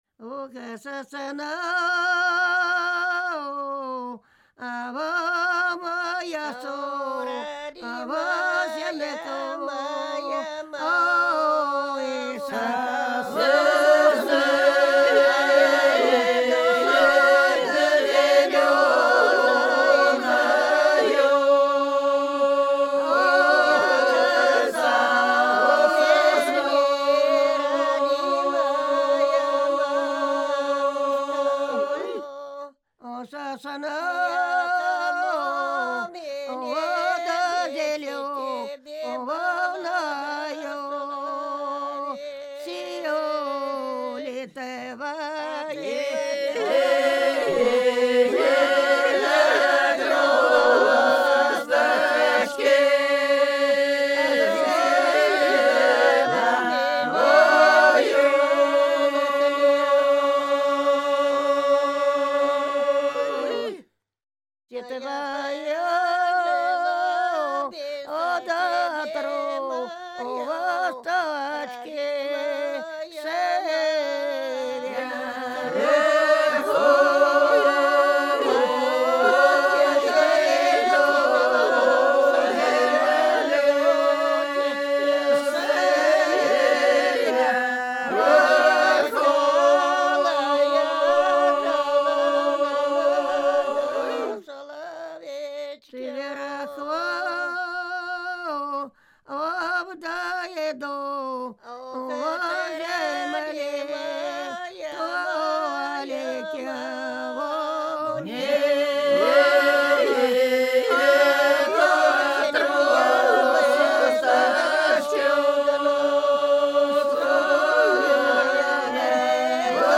Ансамбль села Хмелевого Белгородской области Ох, сосна моя, сосенка/ Родимая моя матушка (свадебная сиротская и голошение невесты-сироты, исполняемые одновременно)